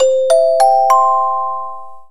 店内放送あたま
デパート等で流れる店内放送の音を作ってみました。「お客様のお呼び出しをいたします」ってやつですね。